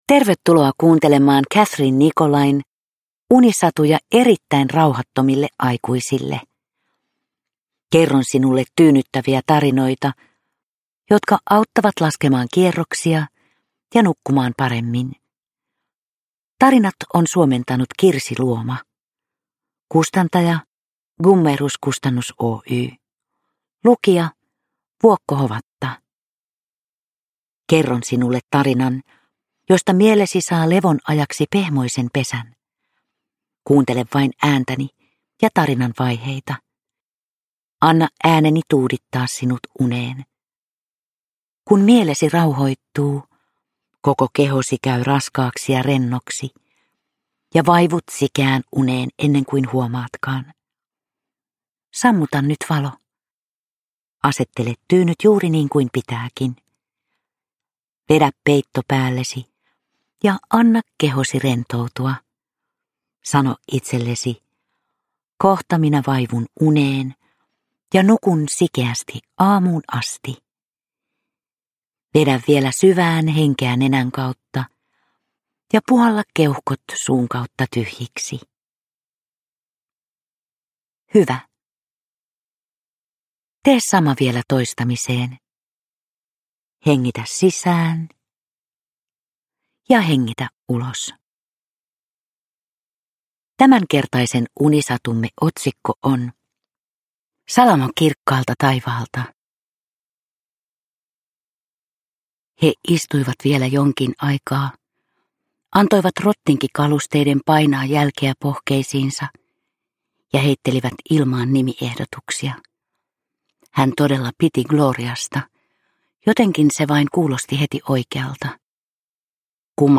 Vuokko Hovatan tyyni ääni saattelee kuulijan lempeästi unten maille.
Uppläsare: Vuokko Hovatta